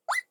Cartoon Quick Zip
banana-peel cartoon comedy exit fweep slip throw whistle sound effect free sound royalty free Funny